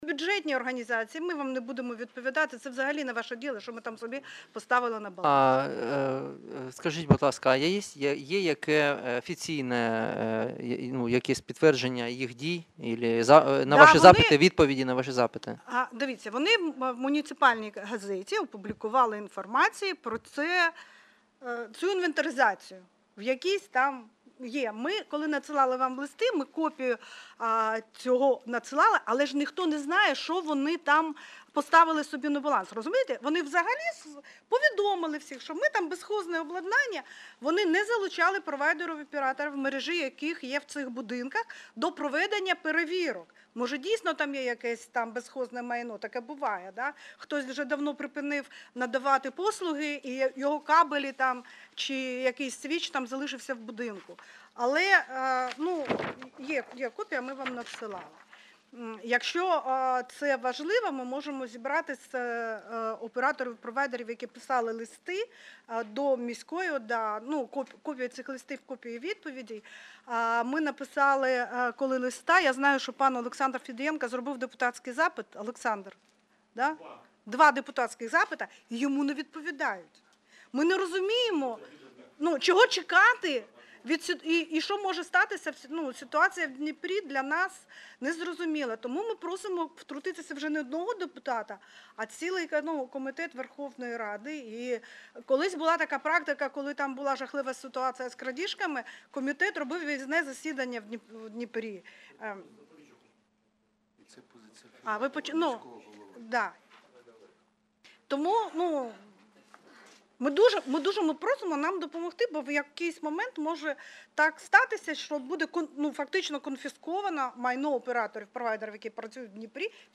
Аудіозапис засідання Комітету від 15.01.2020